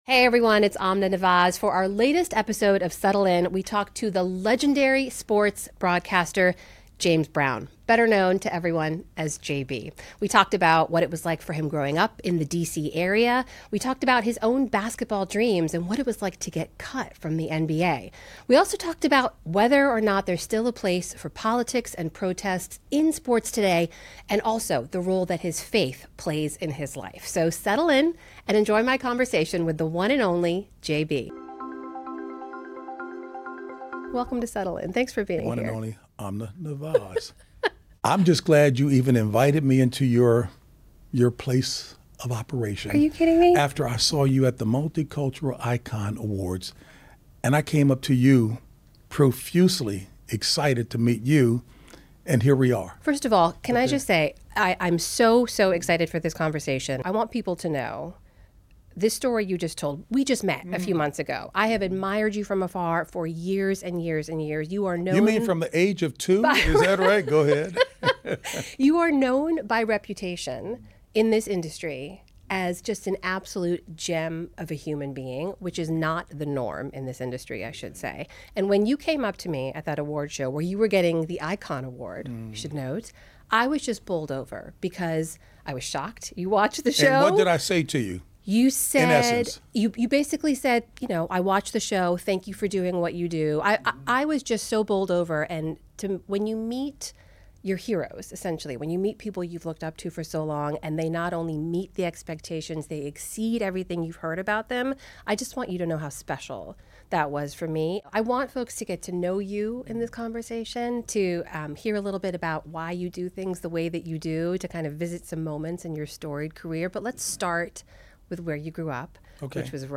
Legendary sportscaster James Brown speaks to Amna Nawaz about his childhood, his faith and how he uses his platform to encourage the NFL community to confront controversial issues.